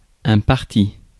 Ääntäminen
US : IPA : [dɪ.ˈsaɪ.pəl]